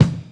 • Sharp Kick Drum One Shot E Key 189.wav
Royality free kick drum single hit tuned to the E note. Loudest frequency: 351Hz
sharp-kick-drum-one-shot-e-key-189-Cxp.wav